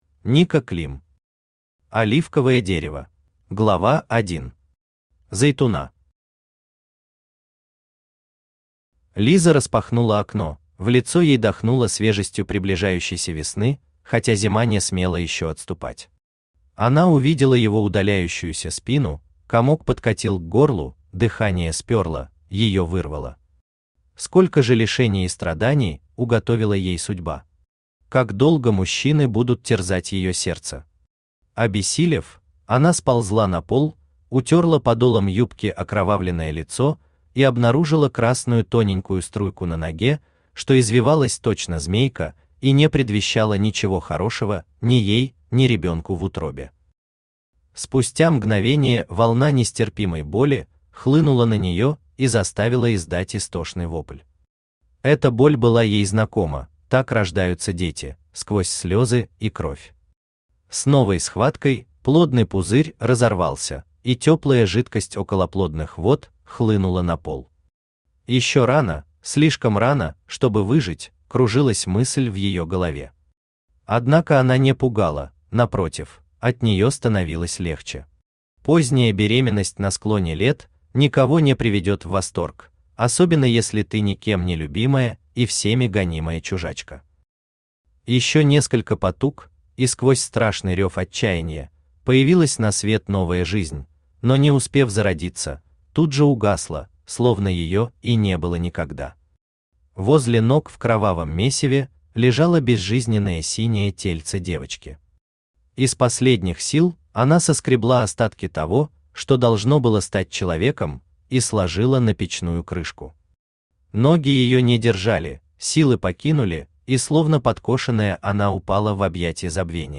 Аудиокнига Оливковое дерево | Библиотека аудиокниг
Aудиокнига Оливковое дерево Автор Ника Клим Читает аудиокнигу Авточтец ЛитРес.